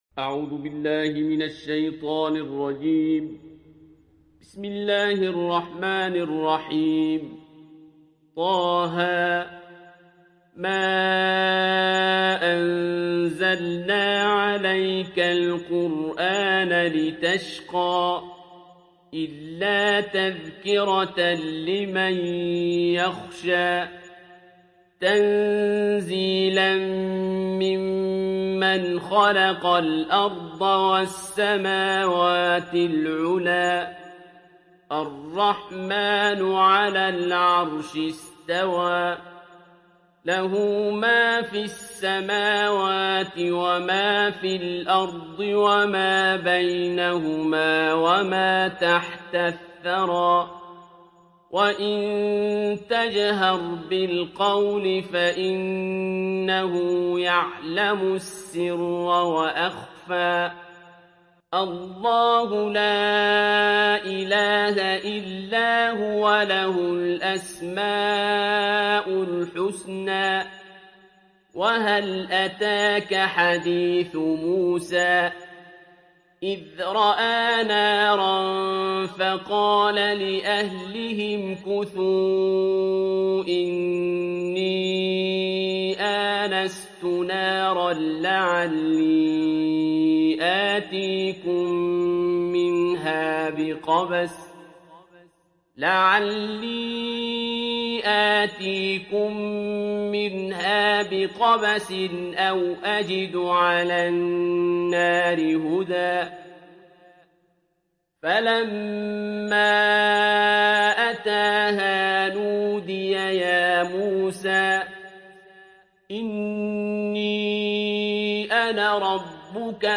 دانلود سوره طه mp3 عبد الباسط عبد الصمد روایت حفص از عاصم, قرآن را دانلود کنید و گوش کن mp3 ، لینک مستقیم کامل